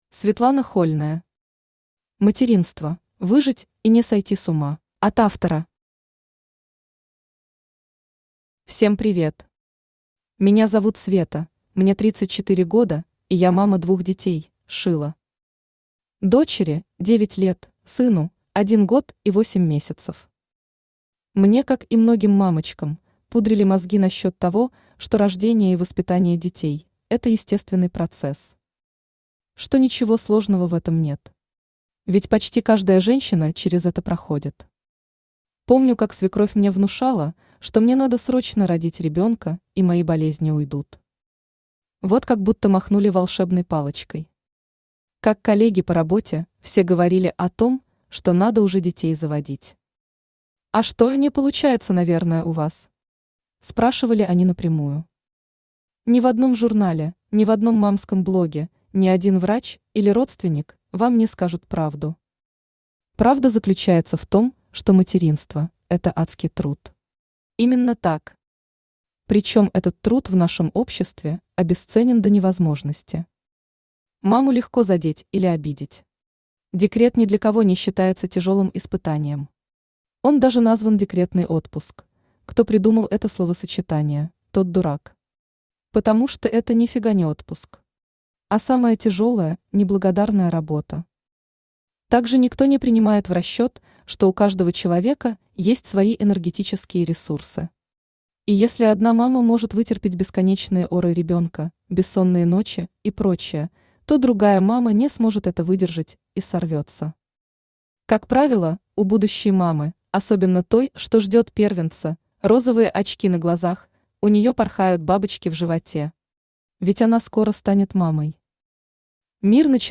Аудиокнига Материнство: выжить и не сойти с ума | Библиотека аудиокниг
Aудиокнига Материнство: выжить и не сойти с ума Автор Светлана Хольная Читает аудиокнигу Авточтец ЛитРес.